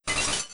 ice_break.wav